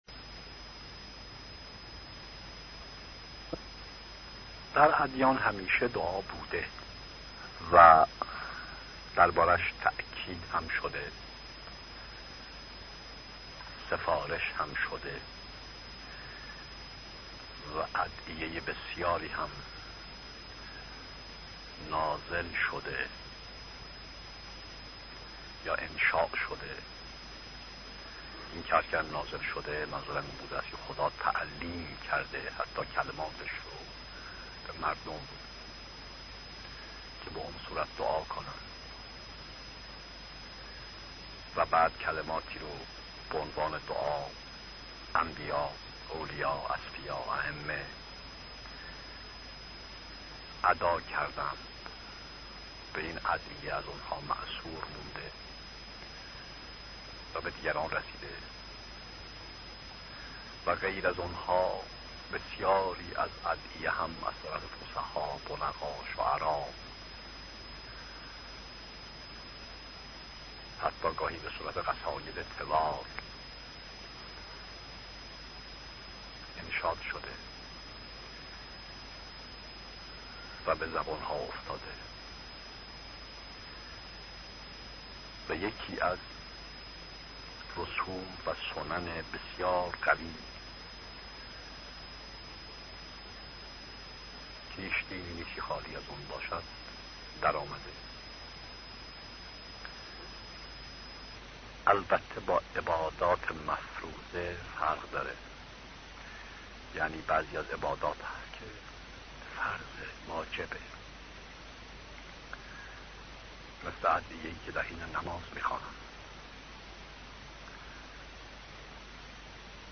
سخنرانی هایی پیرامون عقاید بهائی